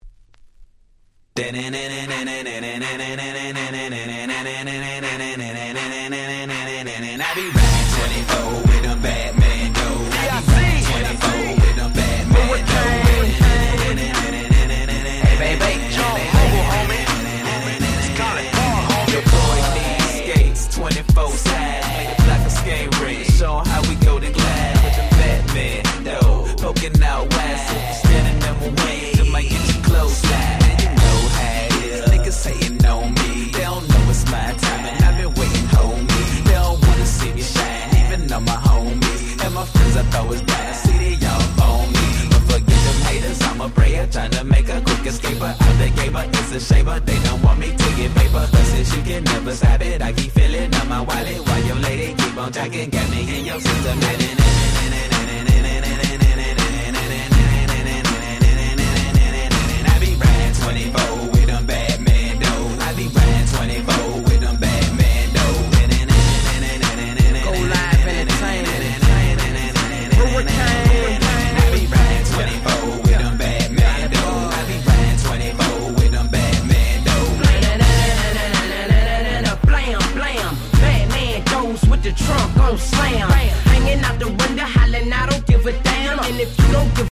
07' Super Hit Southern Hip Hop !!